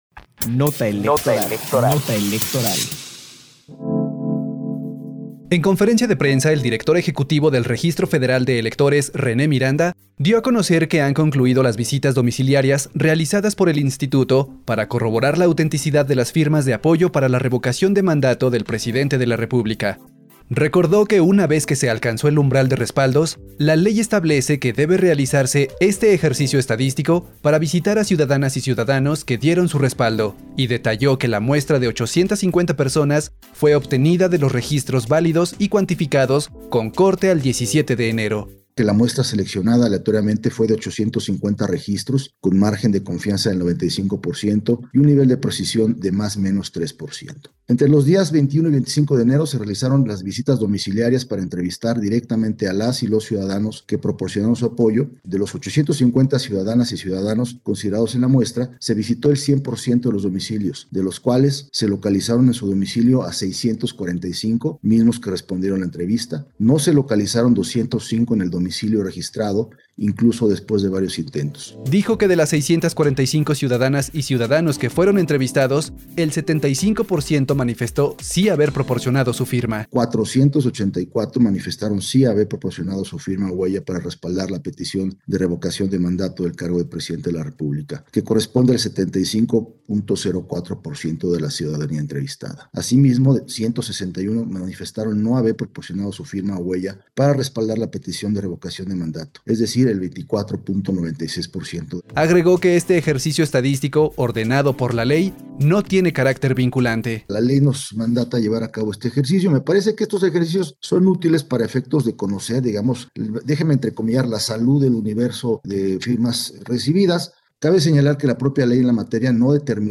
PD_1213_NOTA-ELEC_CONFERENCIA-DE-PRENSA-SOBRE-MUESTRA-APOYOS-CIUDADANOS-RM-27-enero-2022_CE